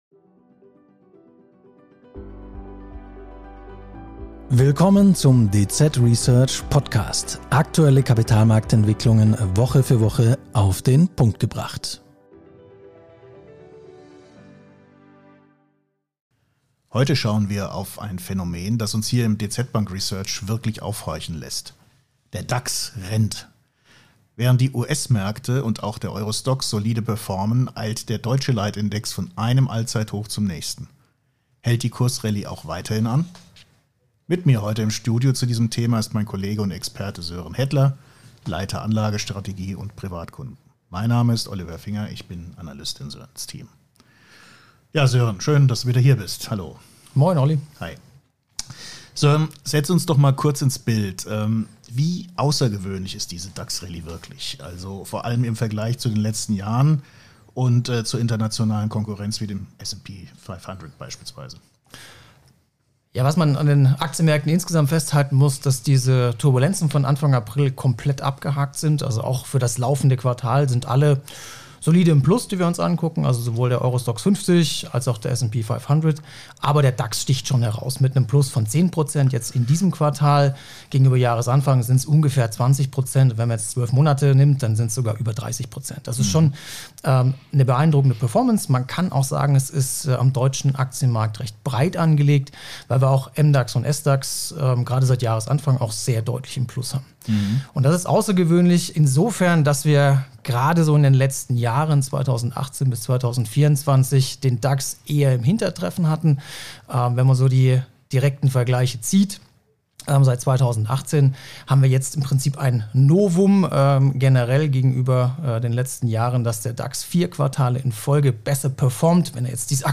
Hält die Kursrallye auch weiterhin an? Mit uns heute im Studio zu diesem Thema ist Experte